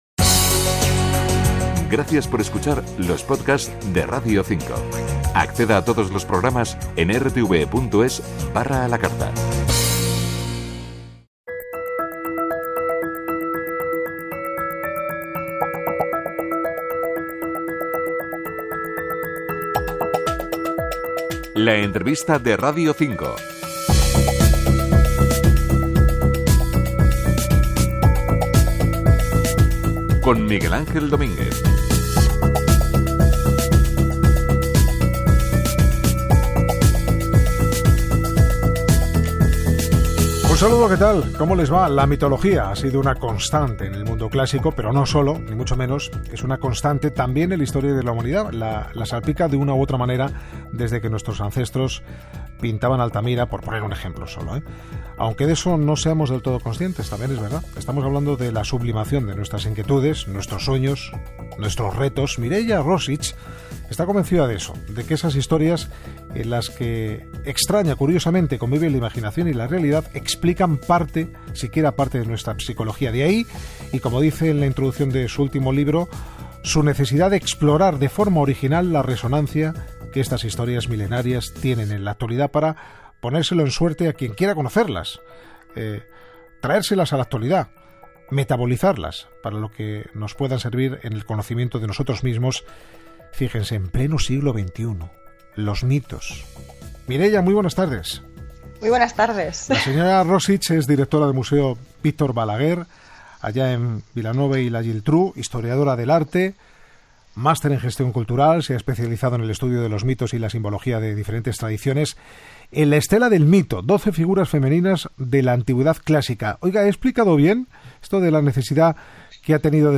Careta del programa, presentació i entrevista